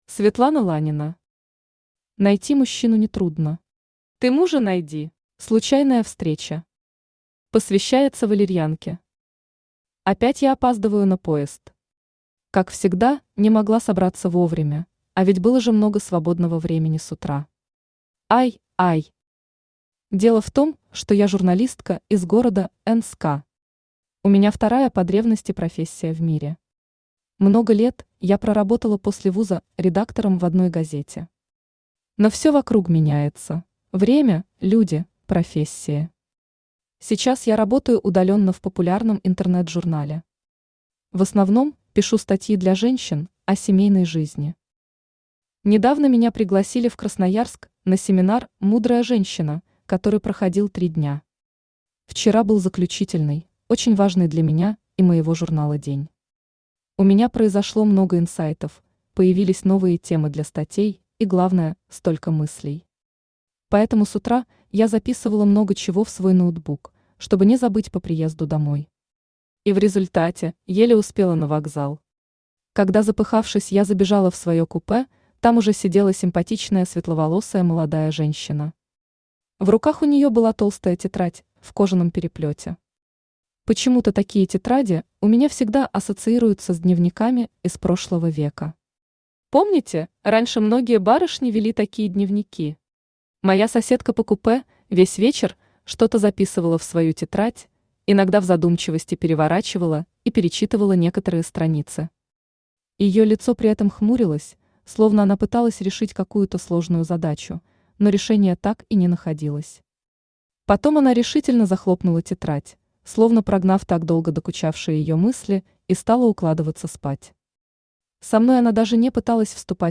Аудиокнига Найти мужчину нетрудно… Ты мужа найди!
Автор Светлана Романовна Ланина Читает аудиокнигу Авточтец ЛитРес.